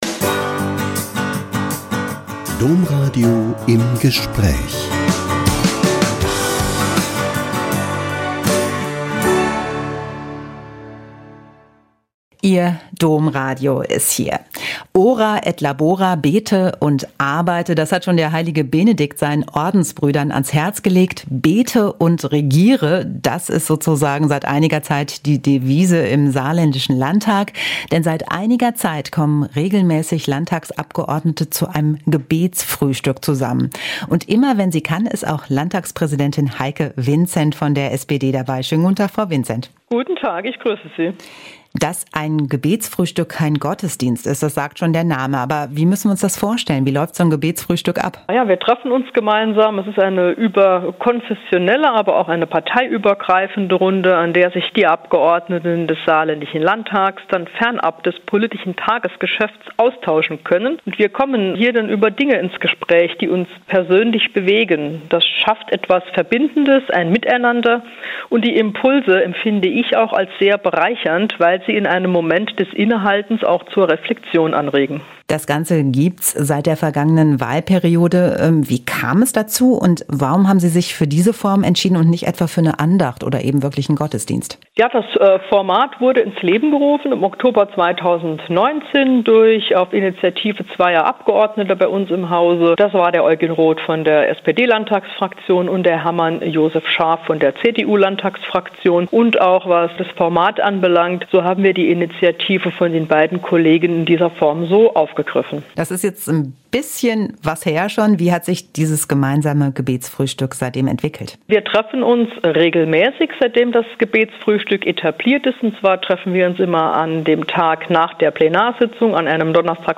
Warum die Landtagspräsidentin das Gebetsfrühstück schätzt - Ein Interview mit Heike Winzent (SPD-Politikerin und Präsidentin des saarländischen Landtags)